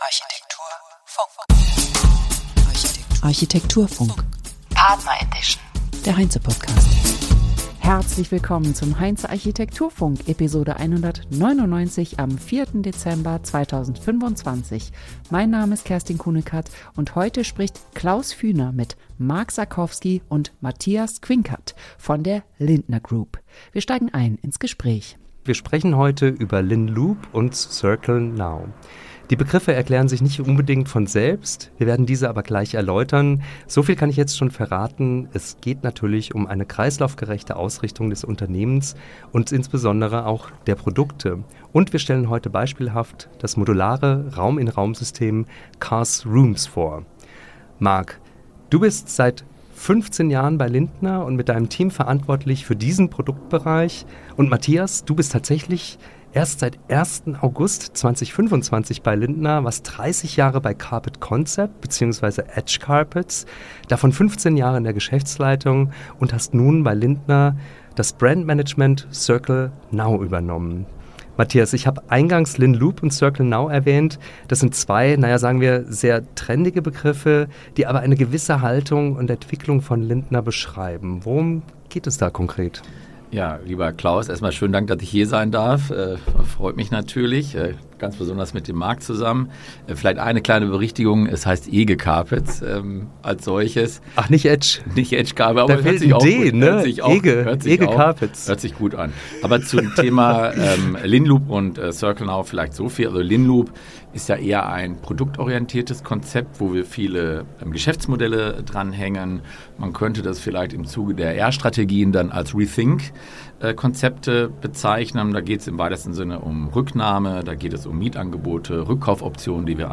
Ein Gespräch über Qualität, Verantwortung und echte Kreisläufe.